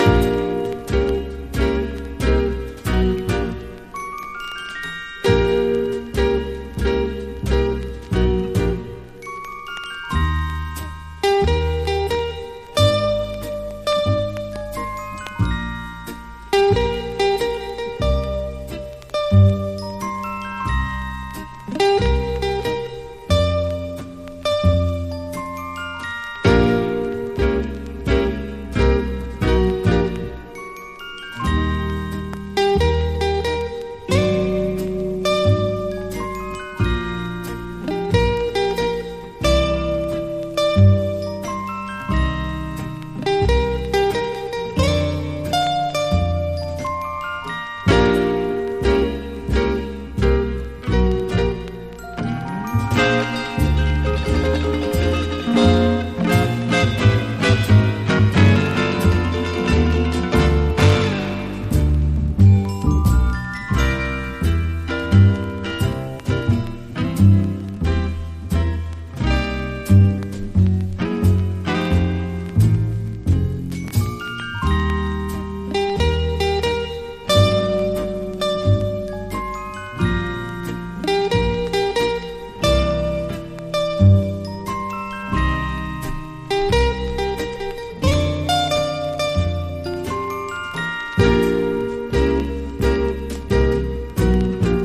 和ジャズ・ギターのトップ二人による1967年の共演盤！